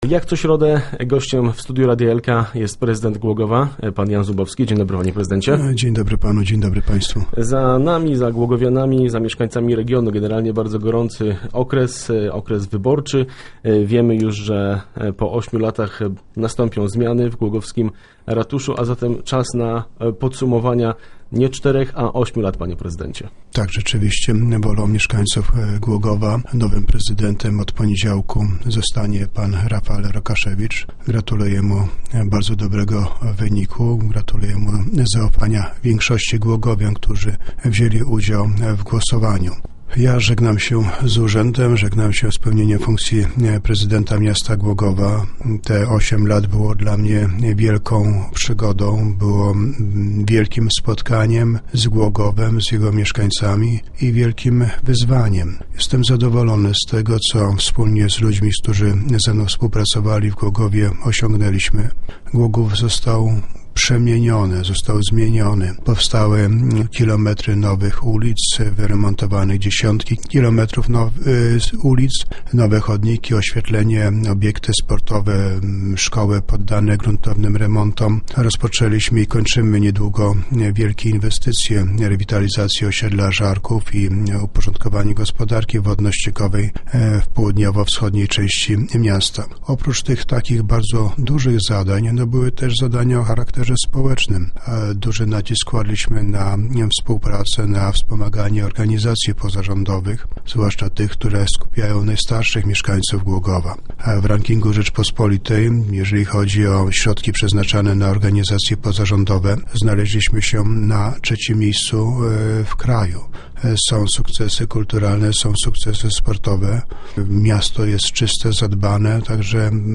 W drugiej turze wyborów musiał uznać wyższość Rafaela Rokaszewicza. Dziś w radiowym studiu odchodzący prezydent podsumował lata swoich rządów.